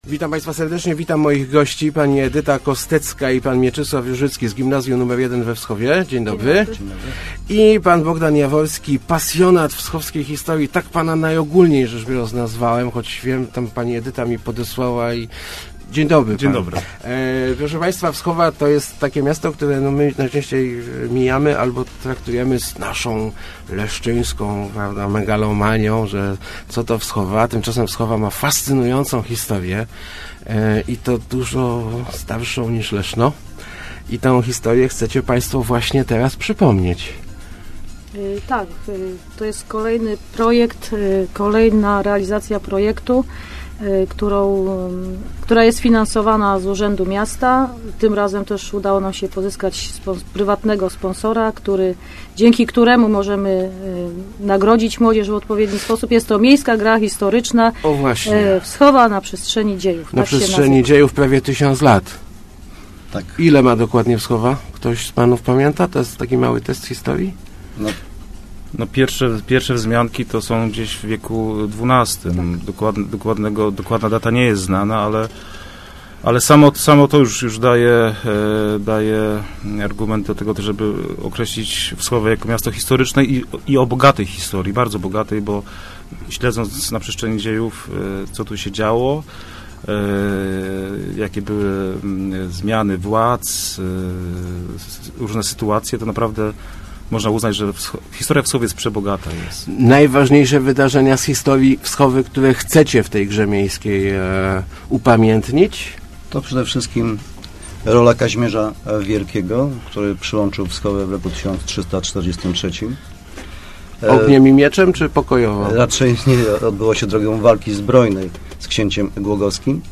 Wschowa też będzie miała swoją miejską grę historyczną. W scenariuszu uwzględnione zostaną najważniejsze wydarzenia z prawie tysiącletniej, niezwykle bogatej historii miasta. – Będzie o czasach Kazimierza Wielkiego, wojny północnej i II wojny światowej – mówili w Rozmowach ...